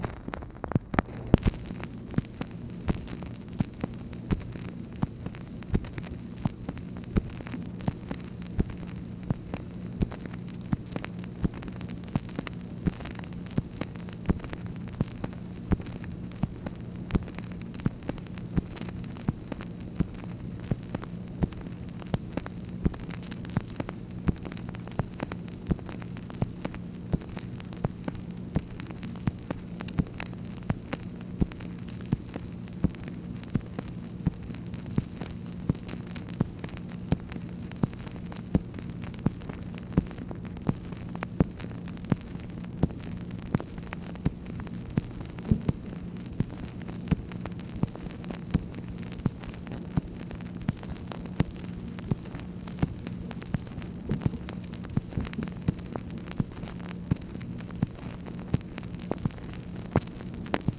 MACHINE NOISE, April 22, 1964
Secret White House Tapes | Lyndon B. Johnson Presidency